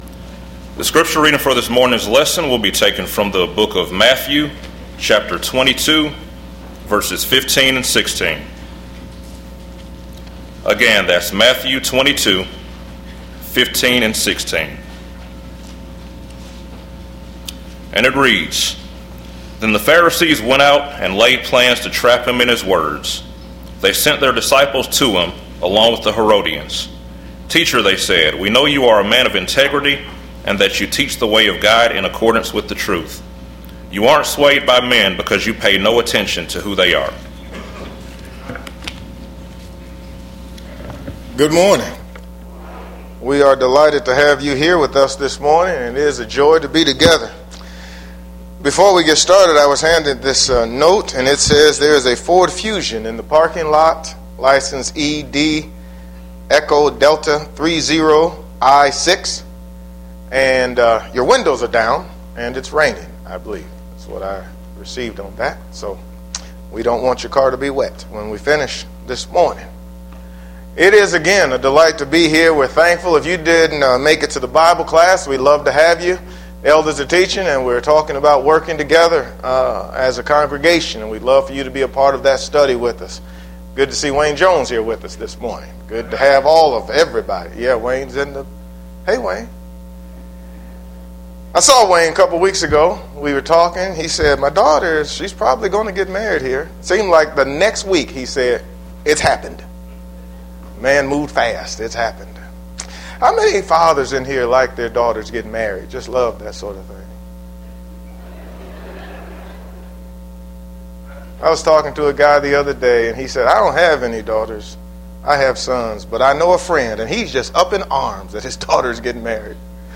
PM Worship